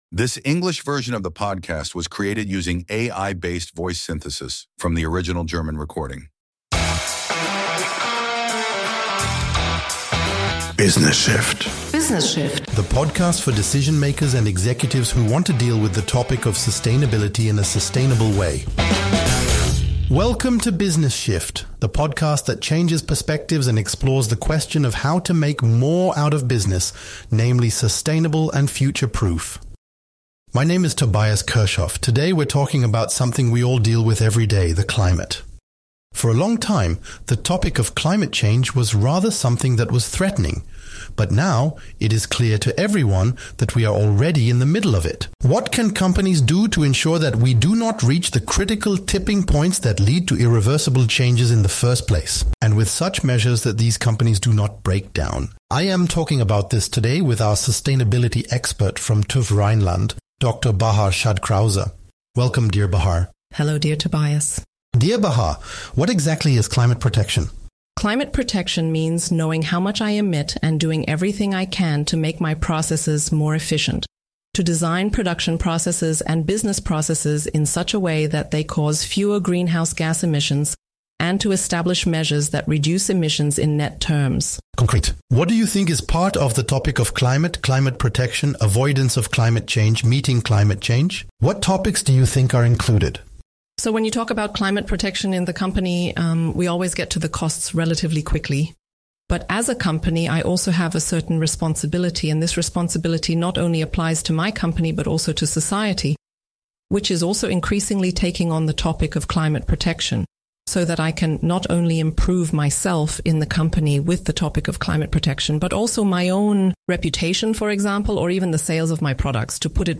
***In compliance with AI regulations, we disclose that the English voices in this podcast episode were generated using artificial intelligence based on the original German version.*** Mehr